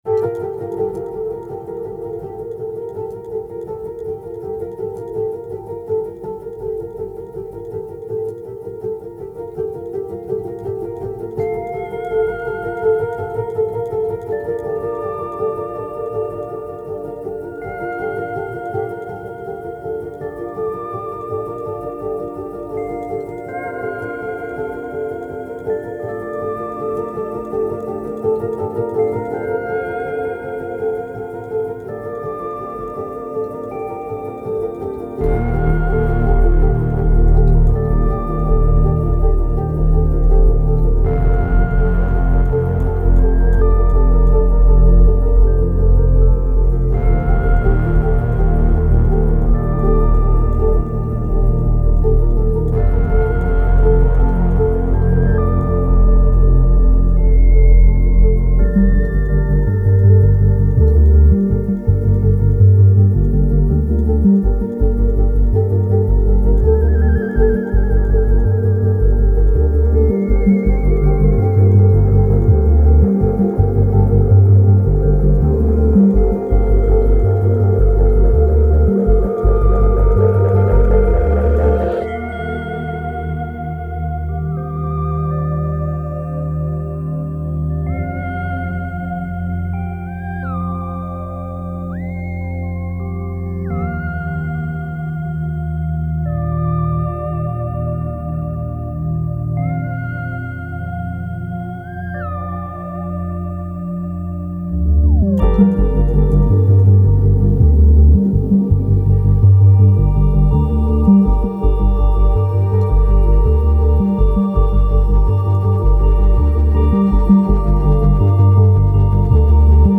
Otherworldly sounds refract and oscillate.